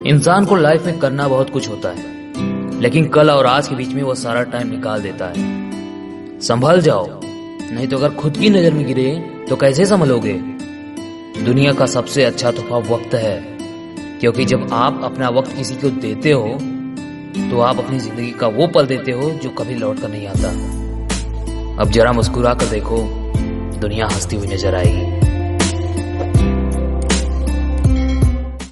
Category Bollywood